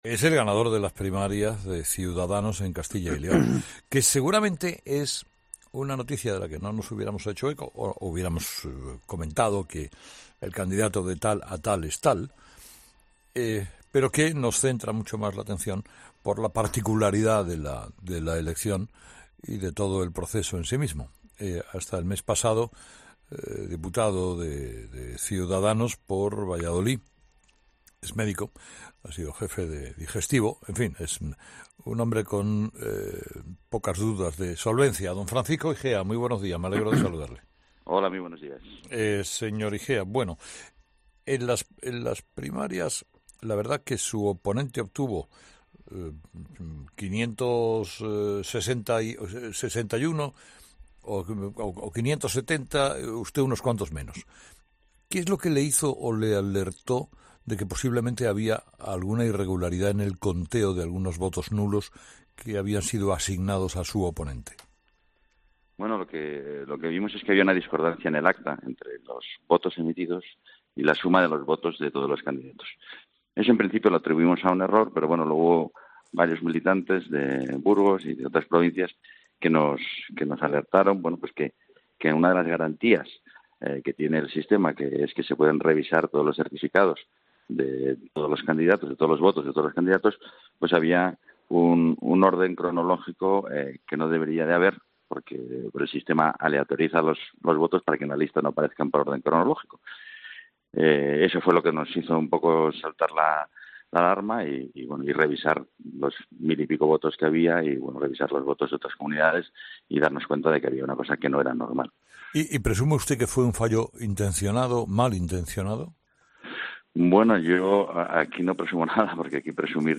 Entrevista a Francisco Igea